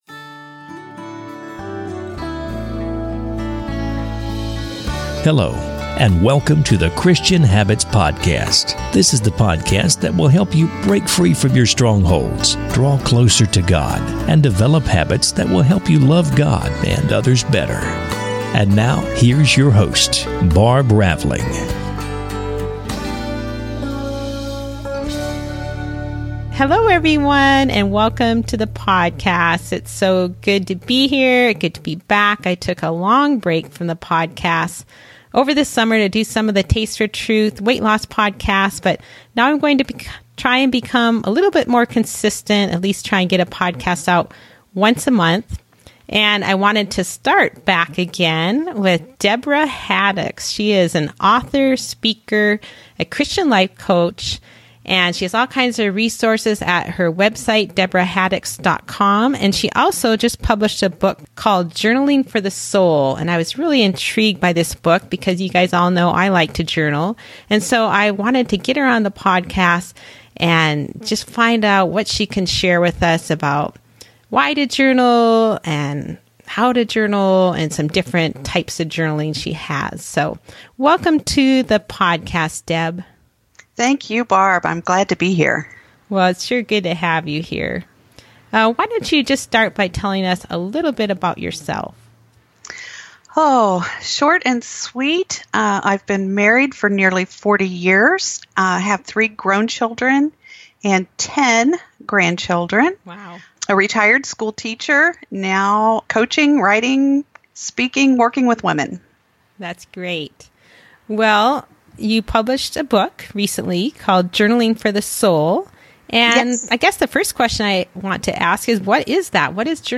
An interview
A podcast interview